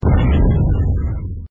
audio_lose.mp3